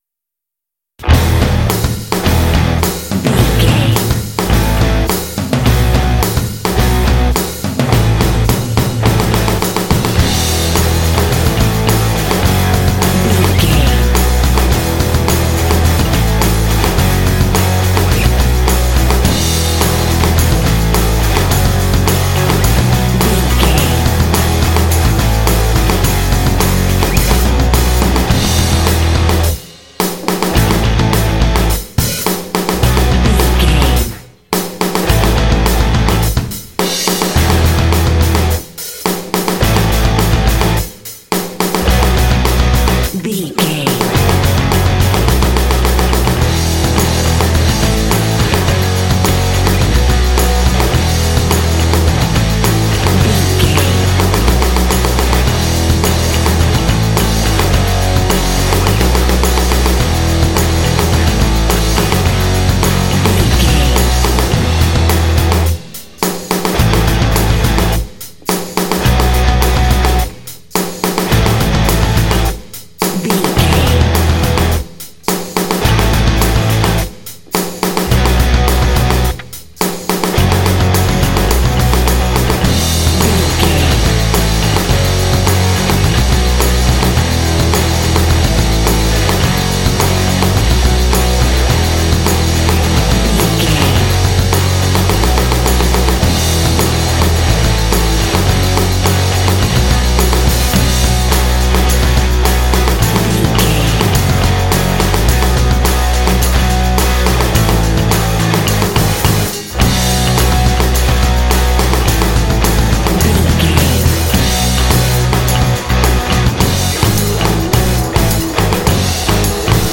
Fast paced
Ionian/Major
groovy
lively
energetic
drums
electric guitar
bass guitar
heavy metal
alternative rock
classic rock